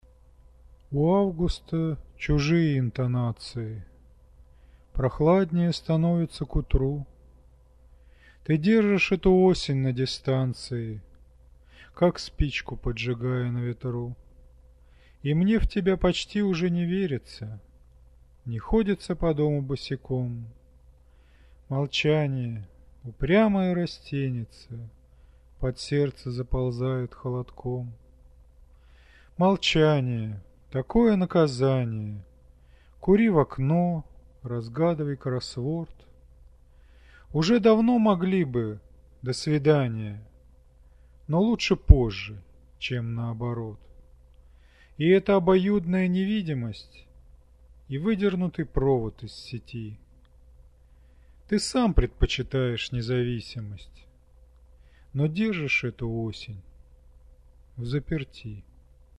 А вот так я развлекался декламацией...
Бить не будем: голос приятный, стихи хорошие и вообще:))